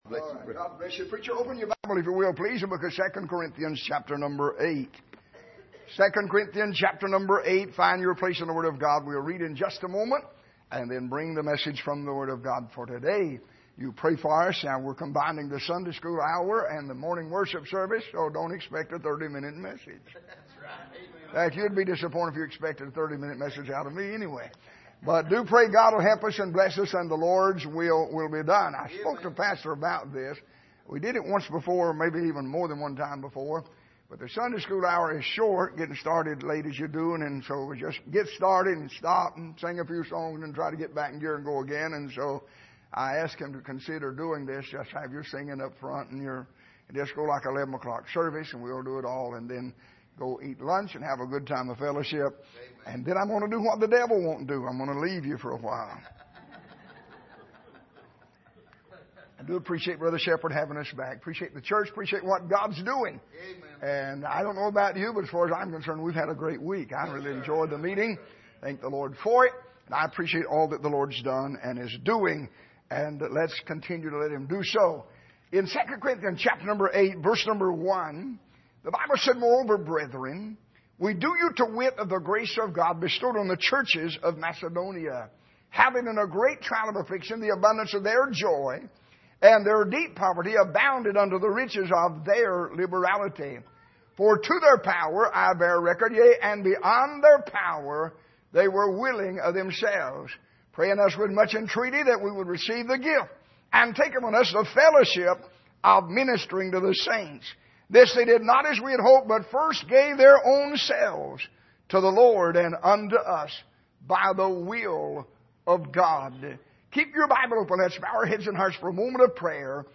Series: 2016 Missions Conference
Service: Sunday Morning